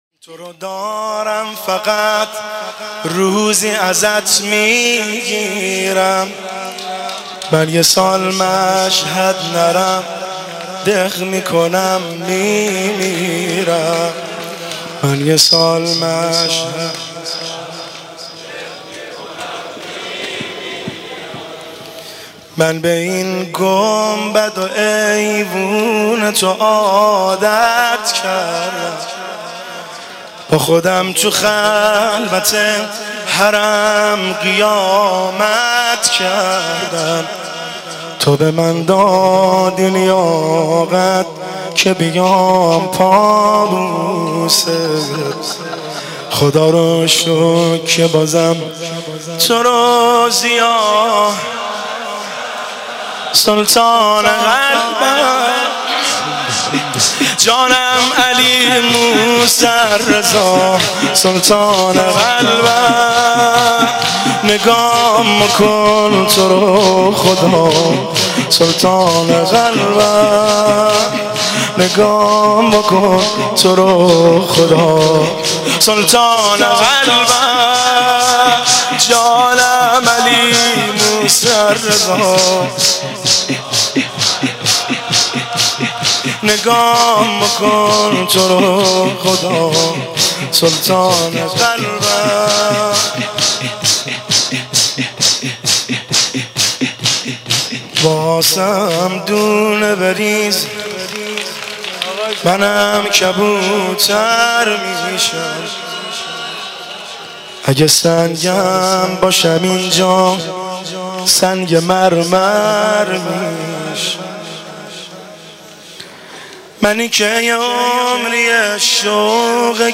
که در بین الحرمین تهران اجرا شده است
شور